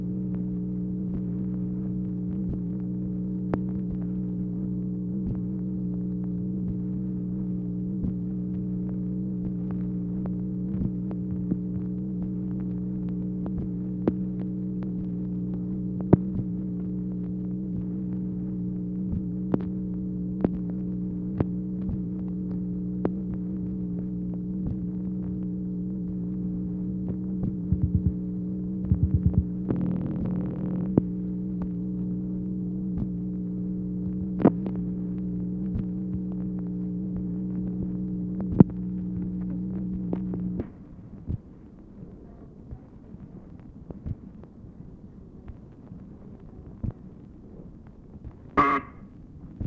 MACHINE NOISE, February 3, 1964
Secret White House Tapes | Lyndon B. Johnson Presidency